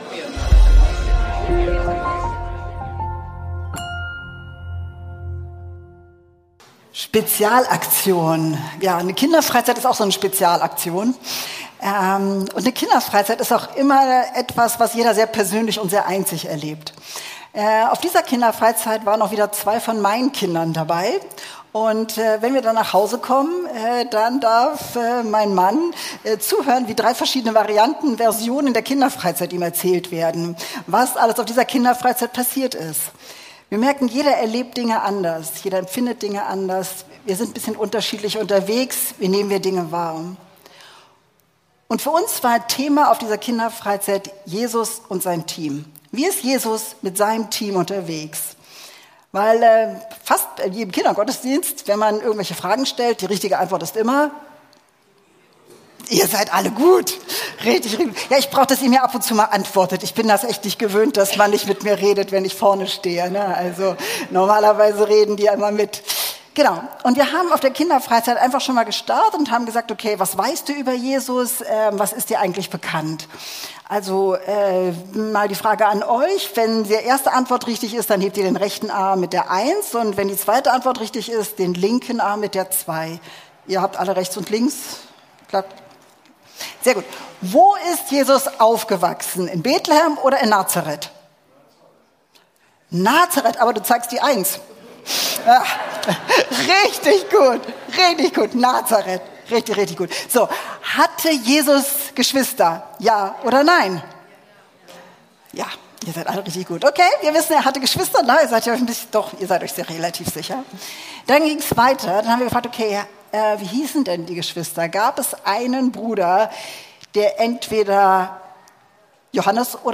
Gottesdienst zur Kinderfreizeit ~ Predigten der LUKAS GEMEINDE Podcast